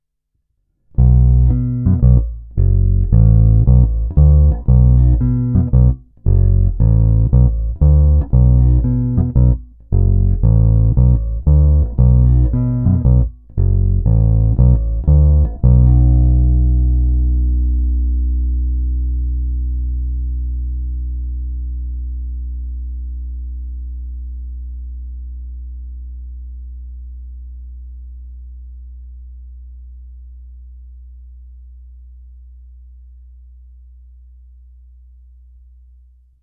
Zvuk je hodně dobrý, na krkový snímač tučný, kobylkový je zase středovější agresívnější, ale přesto nepostrádá tučný basový základ, přes jeho umístění blízko kobylky je až překvapivě použitelný i samostatně.
Není-li uvedeno jinak, následující nahrávky jsou provedeny rovnou do zvukové karty s plně otevřenou tónovou clonou. Nahrávky jsou jen normalizovány, jinak ponechány bez úprav.
Snímač u krku